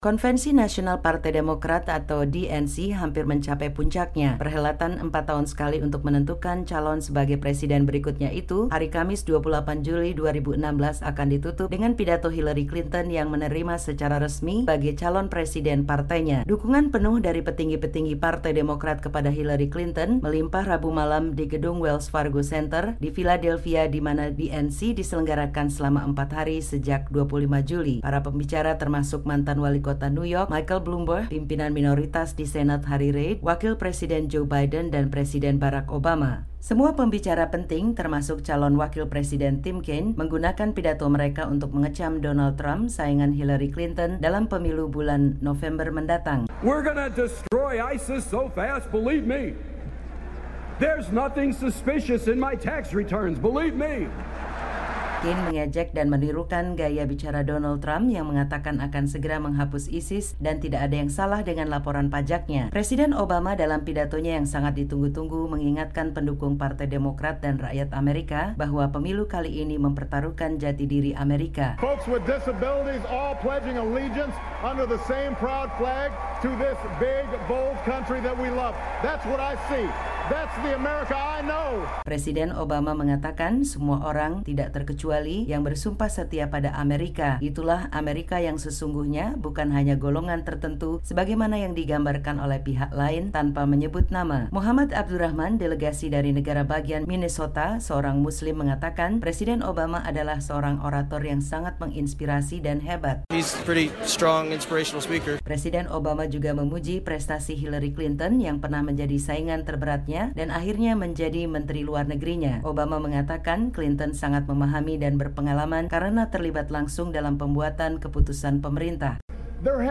Pidato-pidato Hari ke-3 Konvensi Nasional Partai Demokrat